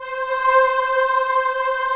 Index of /90_sSampleCDs/AKAI S6000 CD-ROM - Volume 1/VOCAL_ORGAN/BIG_CHOIR
CHOIR-5   -S.WAV